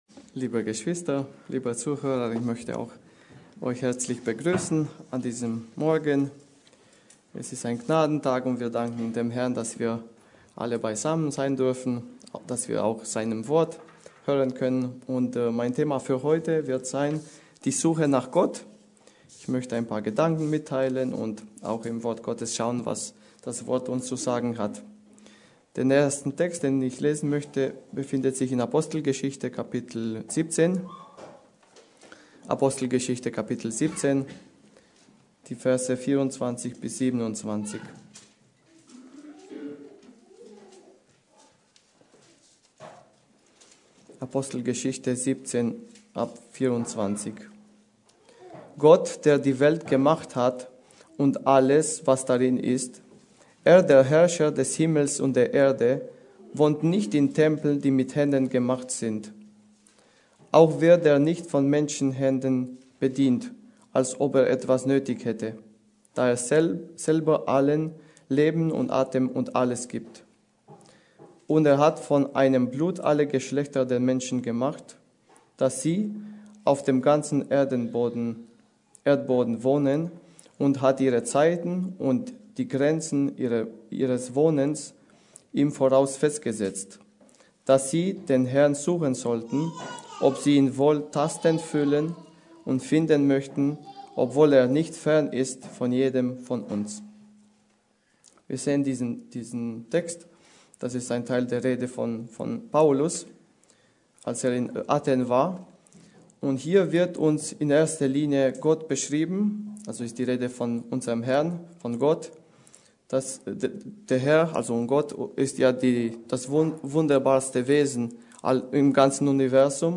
Predigt: Die Suche nach Gott